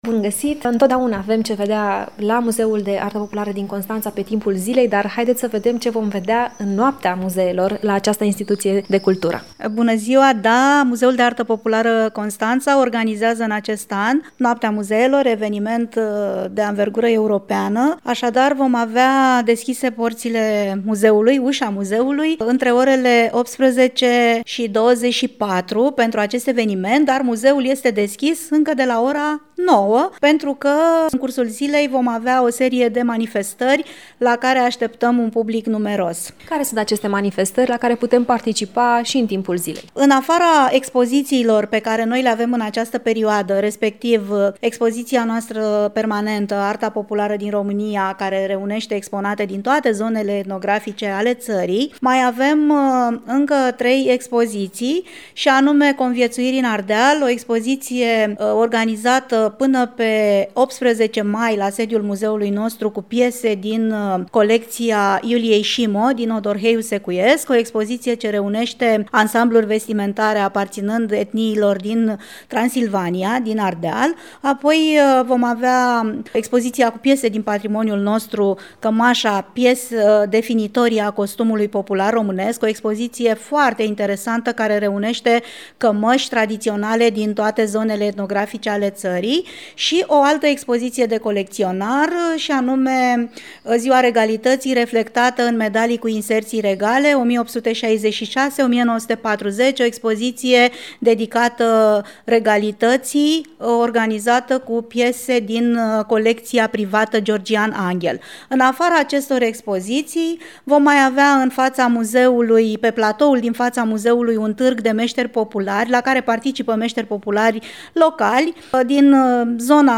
Urmează un interviu